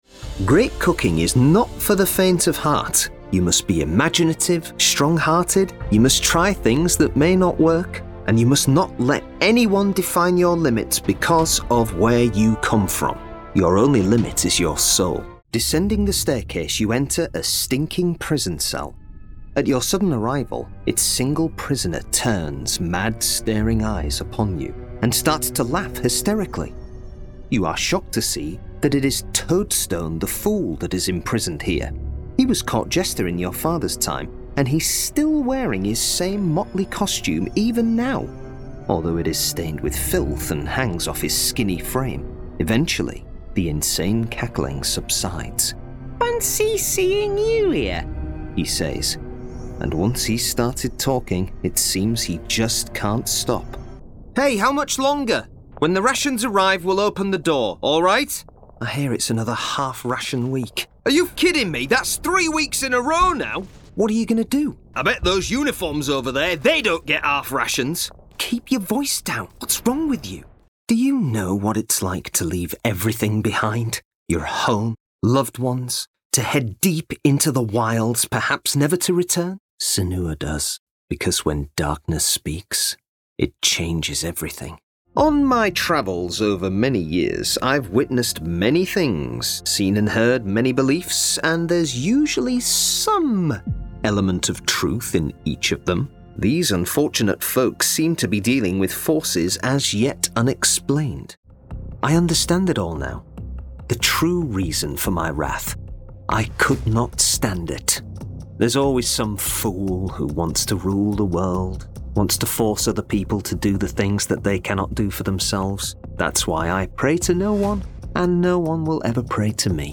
Video Game Voice Overs | Professional Game Voice Talent
Current, versatile, engaging, rich, warm. Natural Yorkshire tones if required, as is heightened RP - BBC 1940's newsreader style.